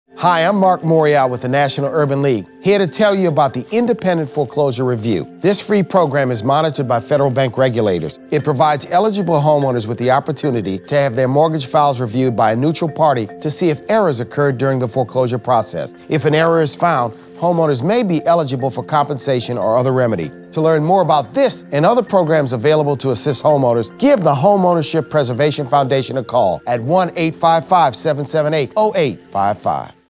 November 9, 2012Posted in: Public Service Announcement